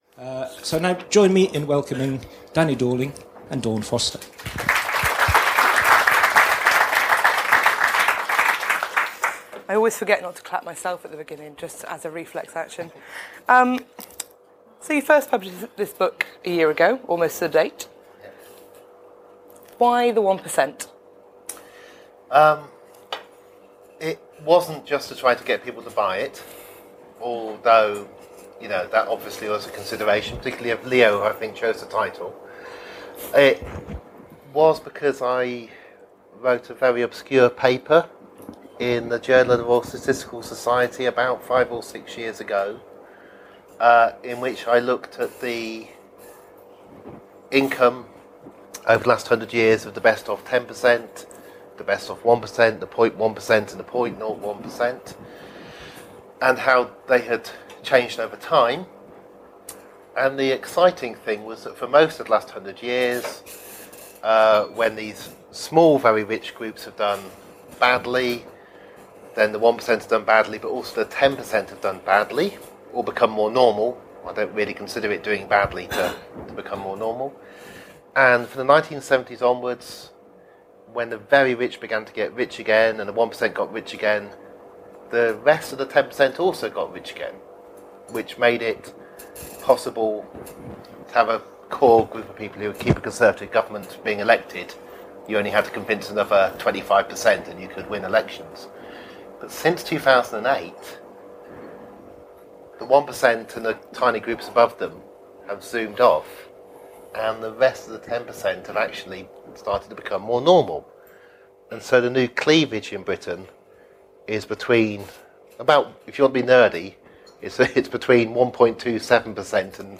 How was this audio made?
London Review of Books Bookshop, London, September 22nd 2015